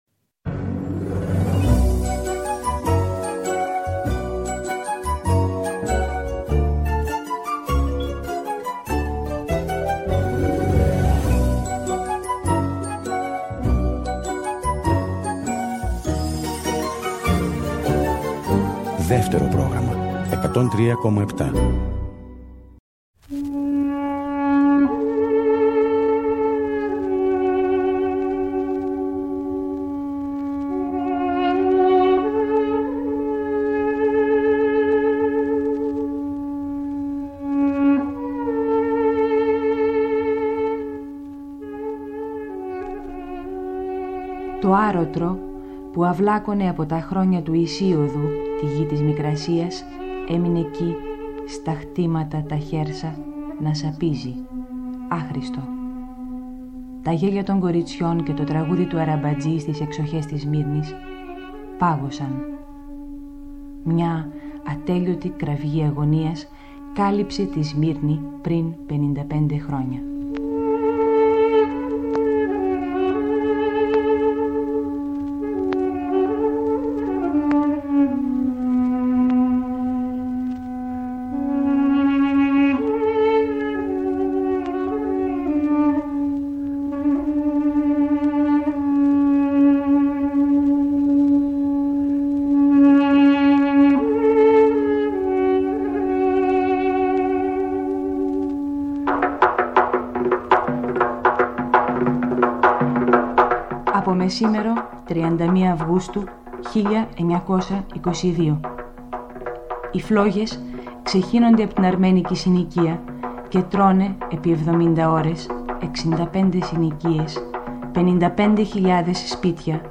Ραδιοφωνικό ντοκιμαντέρ της Μαριάννας Κορομηλά με μαρτυρίες, ιστορικά στοιχεία και ηχητικά ντοκουμέντα. Μεταδόθηκε για πρώτη φορά το 1977 για τα 55 χρόνια από τη Μικρασιατική καταστροφή